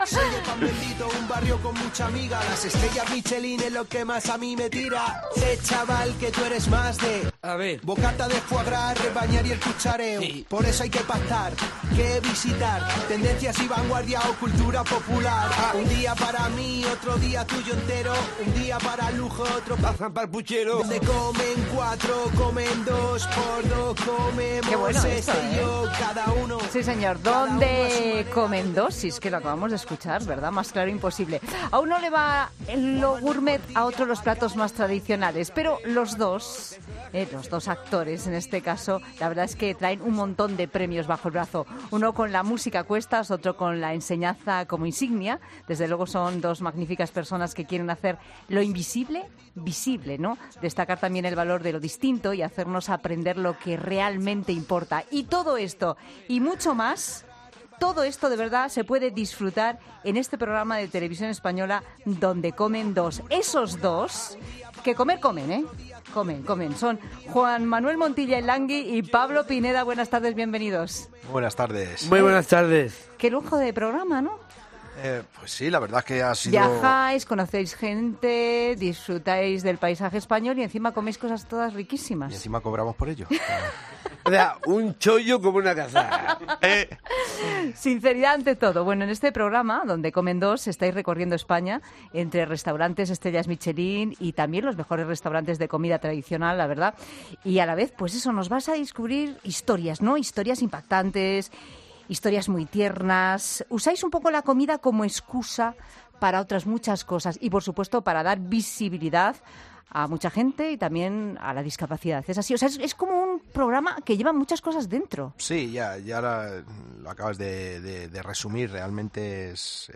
'El Langui' y Pablo Pineda, la pareja televisiva de moda, visitan 'La Tarde'